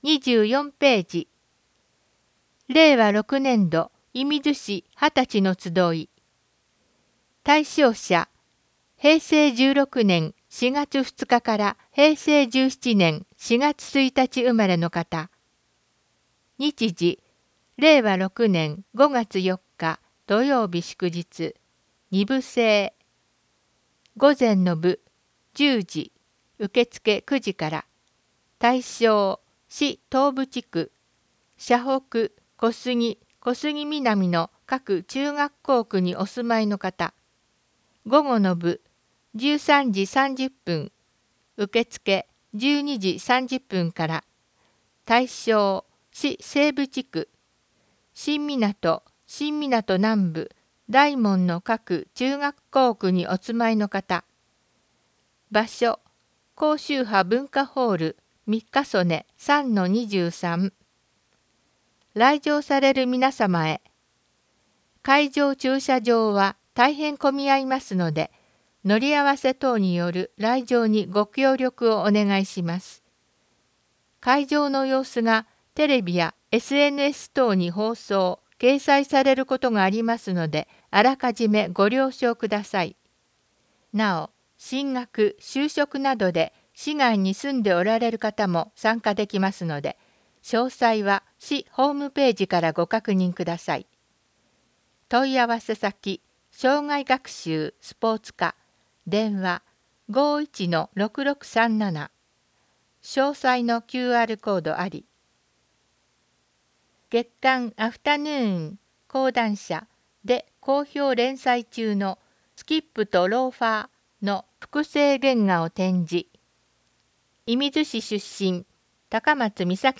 広報いみず 音訳版（令和６年４月号）｜射水市